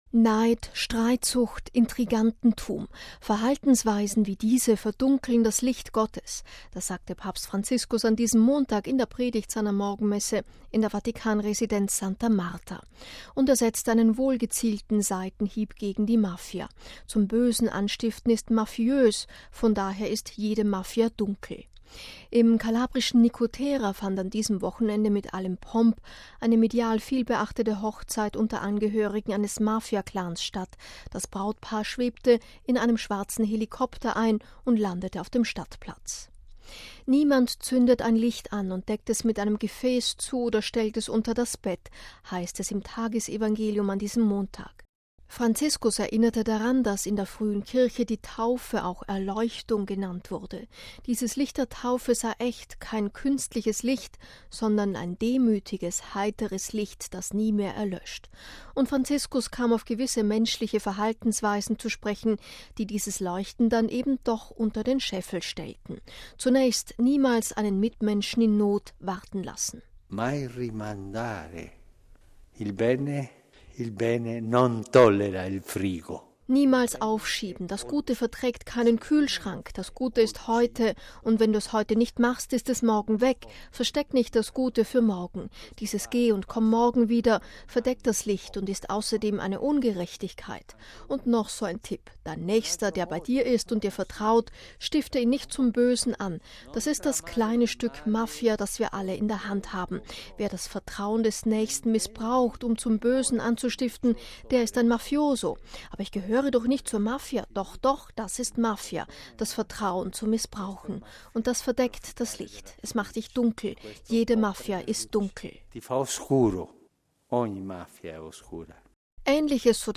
Neid, Streitsucht, Intrigantentum: Verhaltensweisen wie diese verdunkeln das Licht Gottes. Das sagte Papst Franziskus an diesem Montag in der Predigt seiner Morgenmesse in der Vatikan-Residenz Santa Marta.